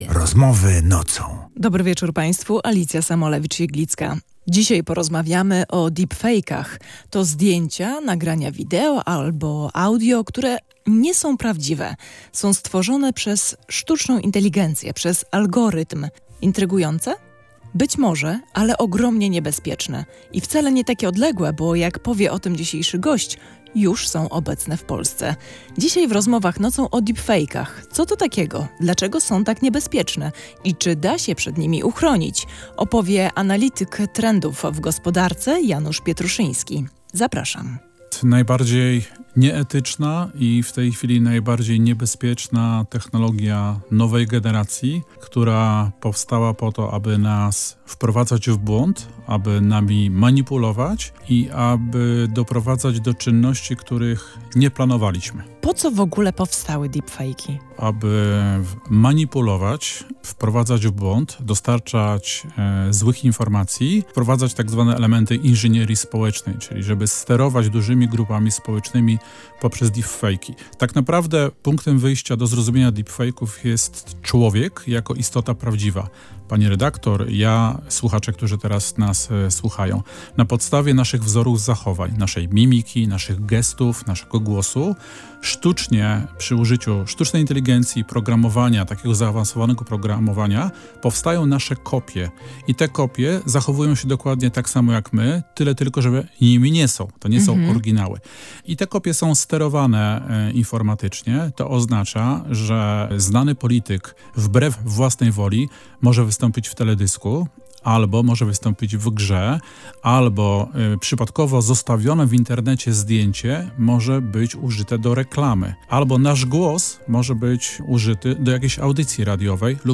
mówił gość audycji „Rozmowy Nocą”